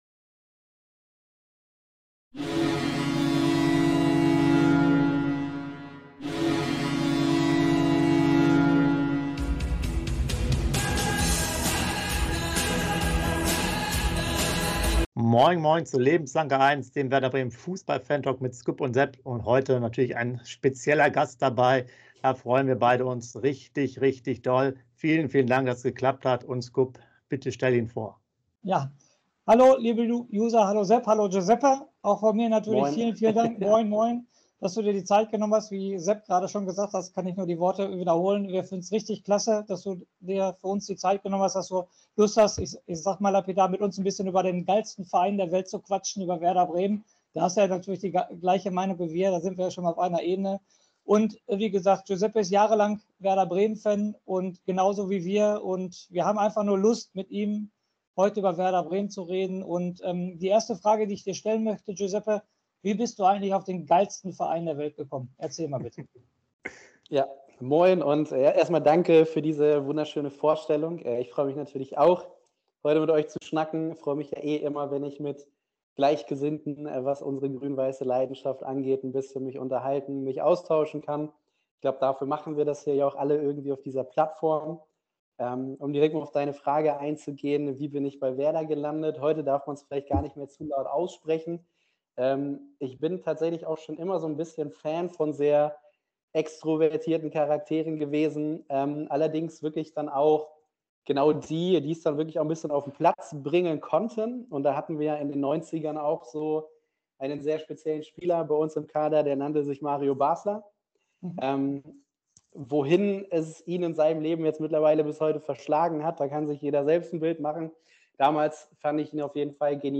Dem Werder Bremen - Fantalk